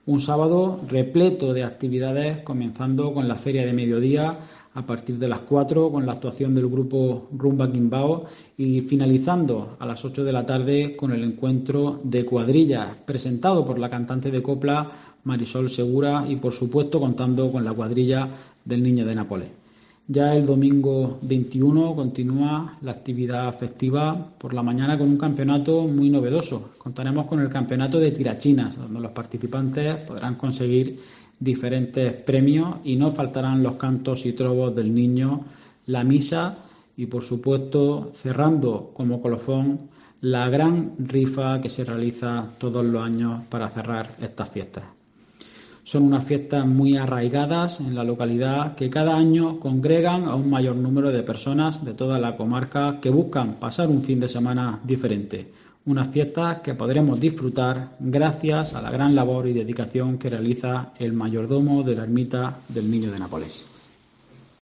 Juan Rubén Burrueco, edil del PP de Puerto Lumbreras